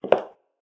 inside-step-1.ogg.mp3